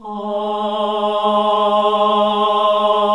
AAH G#1 -L.wav